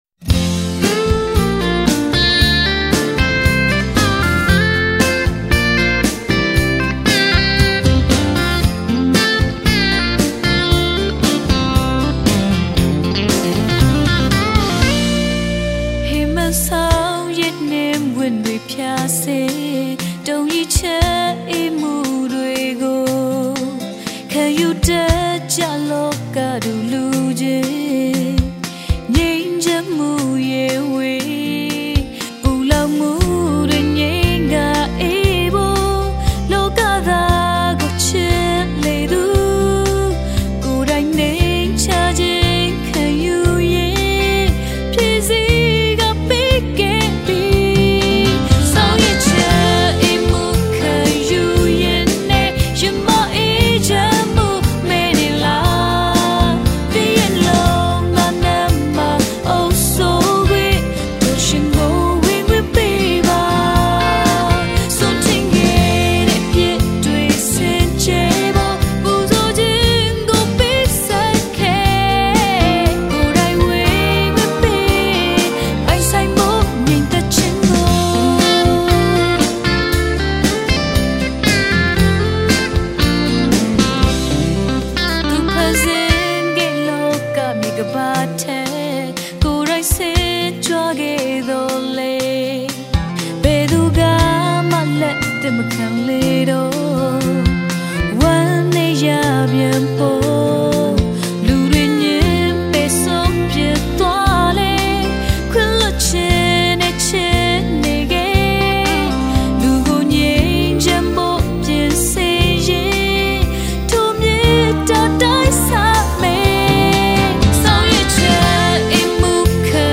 Gospel Songs